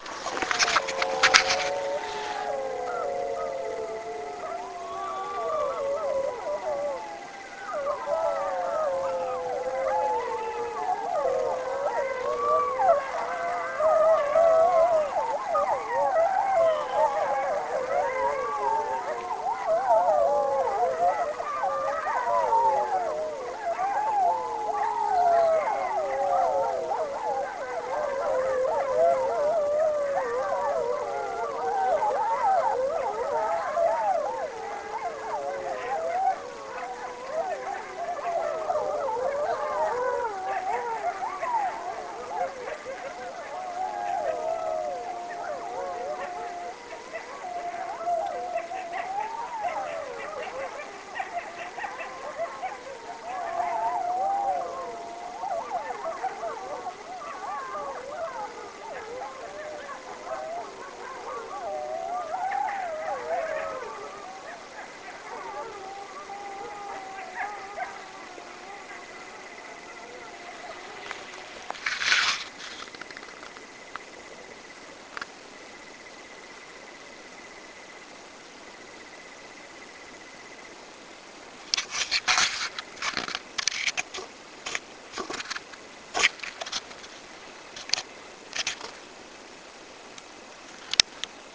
Il pacchetto prevede su richiesta l’esperienza “ricercatore per un giorno” con le attività “sulle tracce del lupo” o “wolf howling – ululati nella notte
Corale branco con cuccioli
escursioni-lupo-Abetone.wav